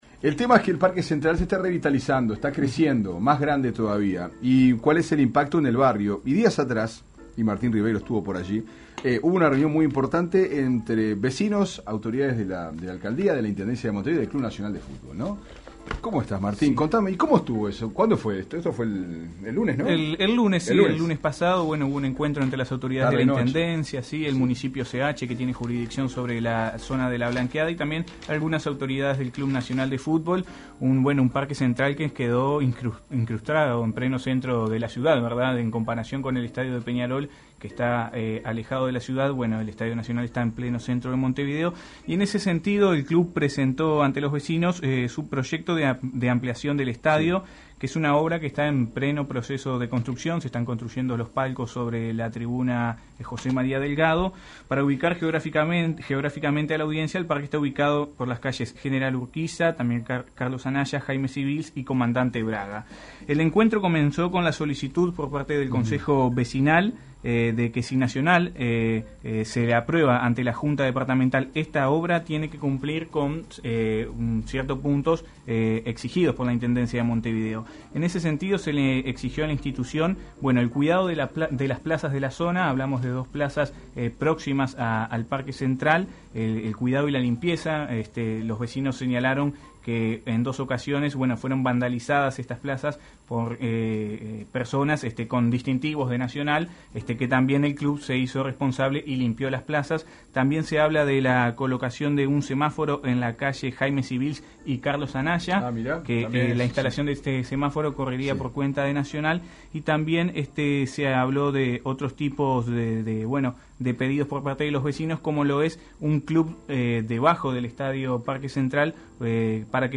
Aquí el informe completo